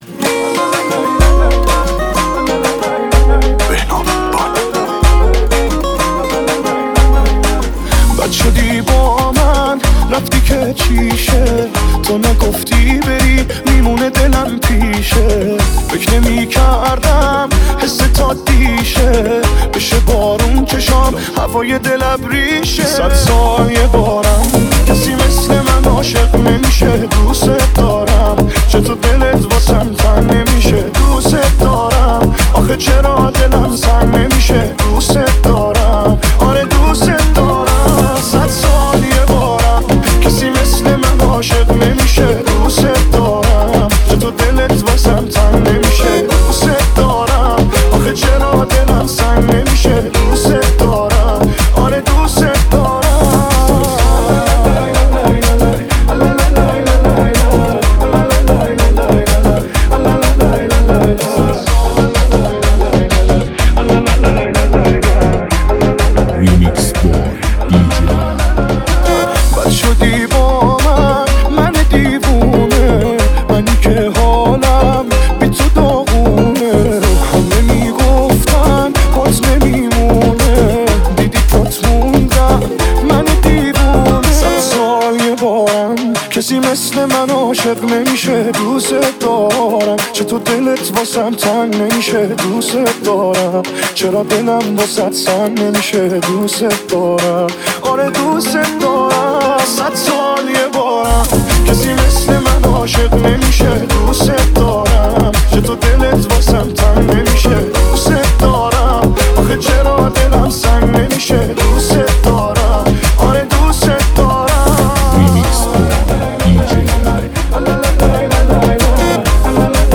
موسیقی پرانرژی و شنیدنی برای لحظاتی پر از احساس و شادی.
با کیفیت بالا و بیس قوی
با بیس سنگین و ریتم جذاب